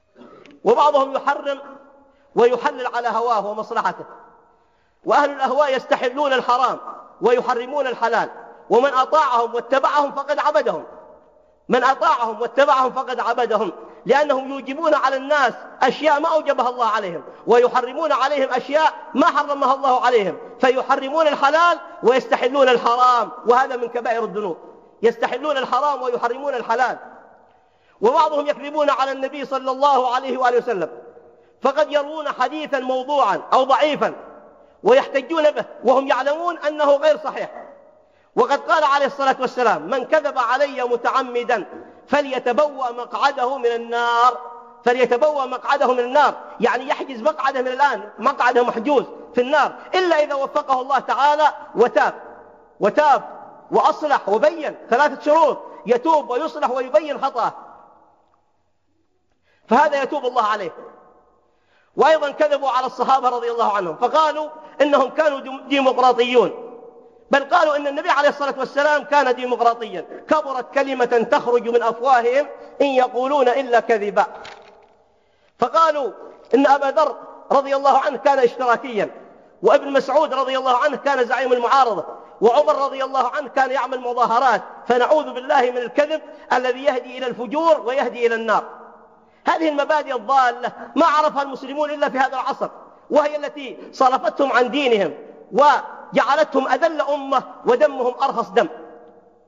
مقطتف بعنوان الكذب على الله ورسوله أقبح الكذب